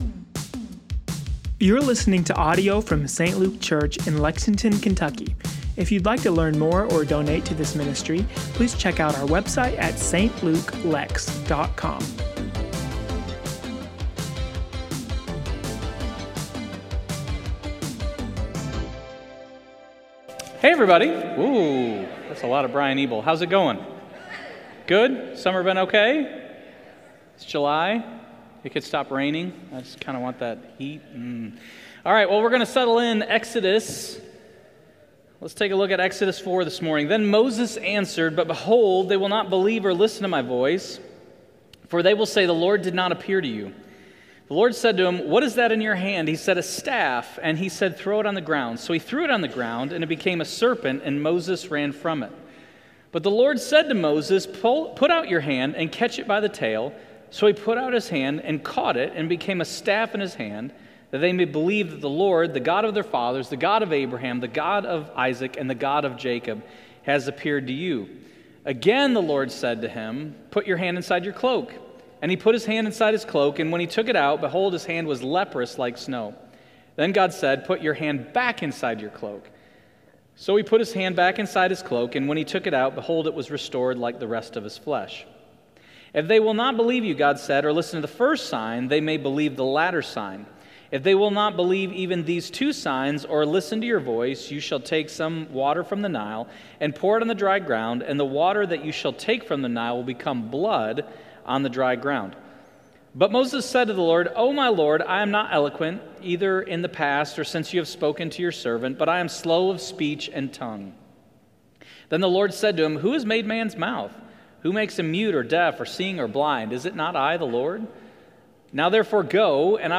St. Luke Church Lexington – Sermons & Teachings